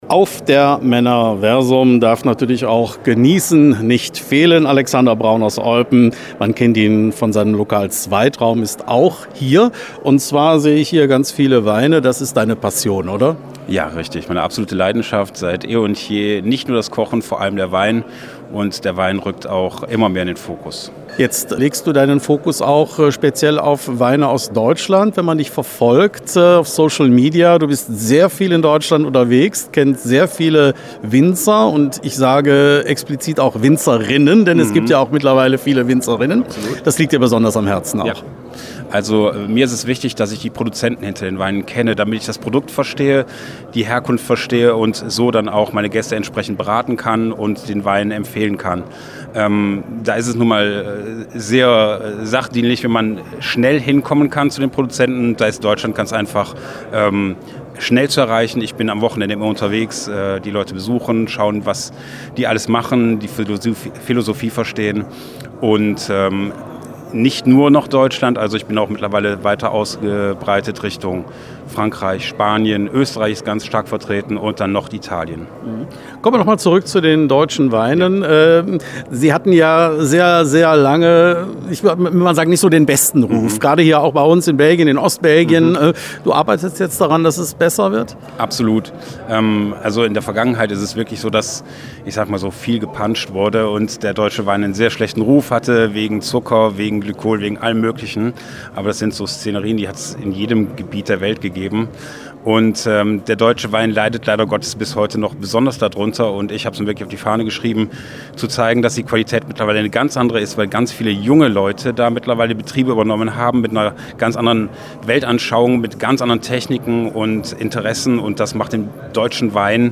Am Wochenende (3. – 5. Oktober 2025) fand in Eupen in der Eastbelgica Eventlocation die Erlebnissemesse „Männerversum“ statt.